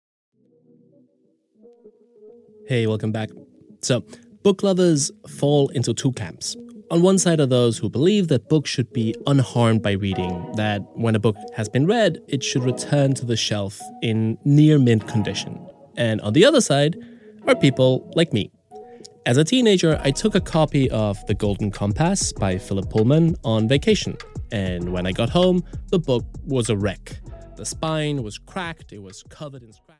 A Theory of Dog Ears (EN) audiokniha
Ukázka z knihy